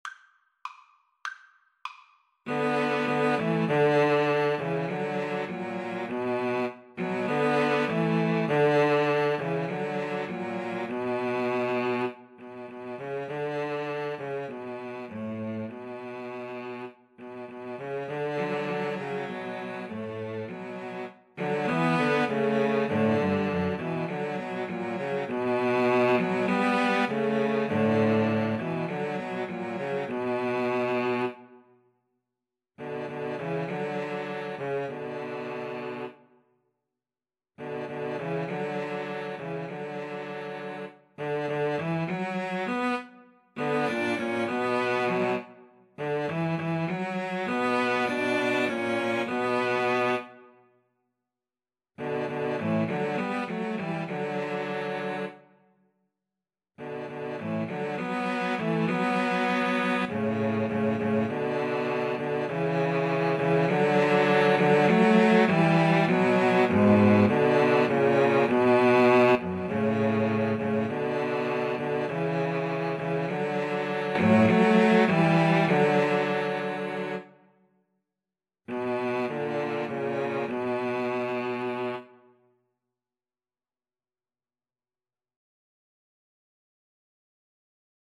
Free Sheet music for Cello Trio
Moderato
B minor (Sounding Pitch) (View more B minor Music for Cello Trio )